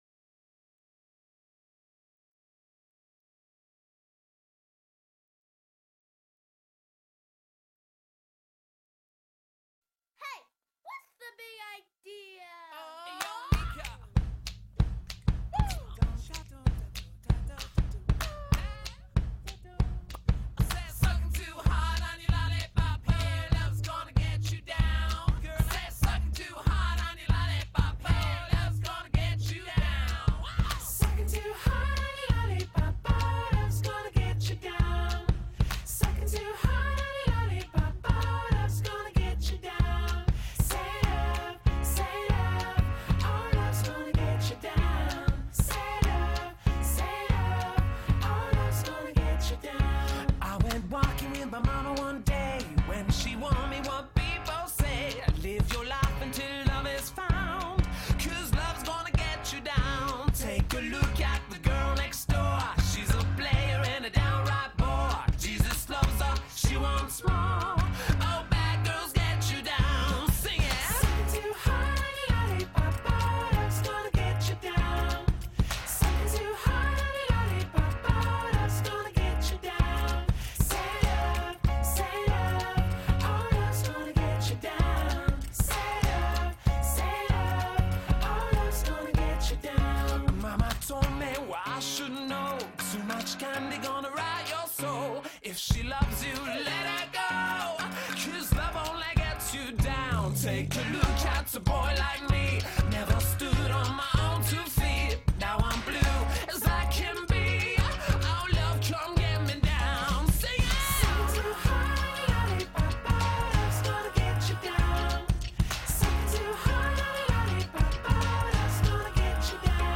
Tags: chill dance workout party